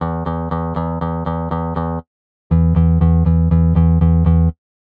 繝ｪ繧｢繝ｻ繝斐ャ繧ｯ繧｢繝繝励ｒ繝悶Μ繝繧ｸ蟇繧翫√ロ繝繧ｯ蟇繧翫↓縺励◆髻ｳ濶ｲ縺ｧ縺吶よ怙蛻昴ｮ貍泌･上ｯ繝ｪ繧｢繝ｻ繝斐ャ繧ｯ繧｢繝繝励′繝悶Μ繝繧ｸ蟇繧翫↓縺ゅｋ縺溘ａ縲√す繝｣繝ｼ繝励〒霈ｪ驛ｭ縺後ｯ縺｣縺阪ｊ縺励◆髻ｳ濶ｲ縺ｫ縺ｪ縺｣縺ｦ縺縺ｾ縺吶
谺｡縺ｮ貍泌･上ｯ繝ｪ繧｢繝ｻ繝斐ャ繧ｯ繧｢繝繝励′繝阪ャ繧ｯ蟇繧翫↓縺ゅｋ縺溘ａ縲∝､ｪ縺丈ｸｸ縺ｿ縺ｮ縺ゅｋ髻ｳ濶ｲ縺ｫ縺ｪ縺｣縺ｦ縺縺ｾ縺吶